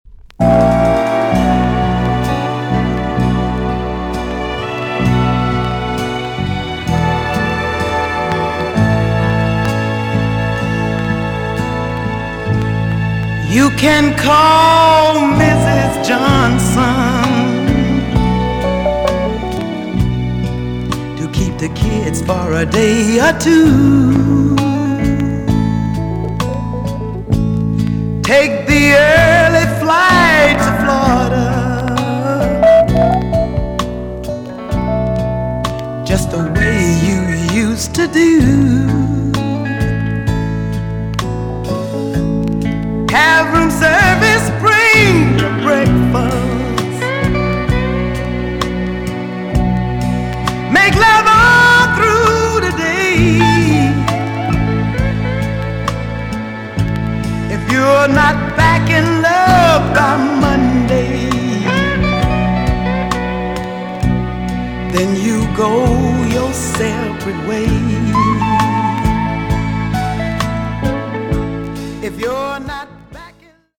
EX- 音はキレイです。
1977 , NICE SOUL TUNE!!